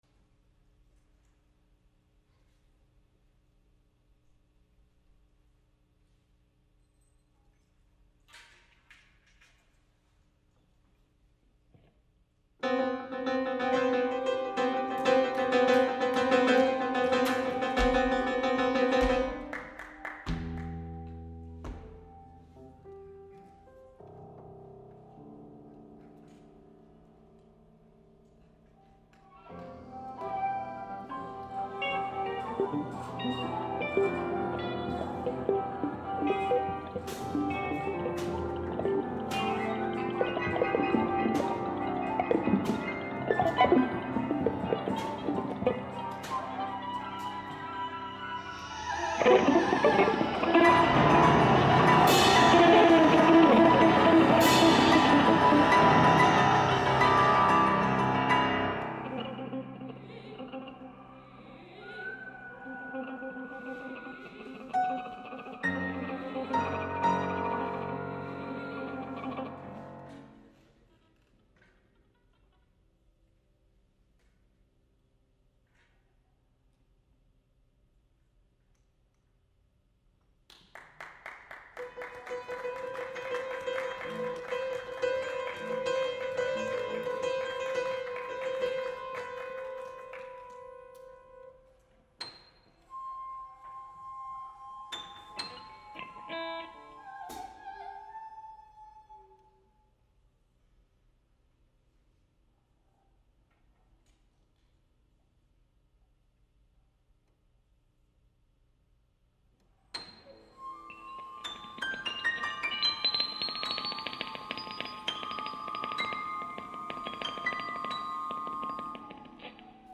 for flexible instrumentation with live performers & stopwatches
recorded live at Crowell Concert Hall, Wesleyan University, 2003
soprano
amplified bowed piano
tape loops
piano and kick drum
bass flute
flute and piccolo
alto flute
viola
electric guitar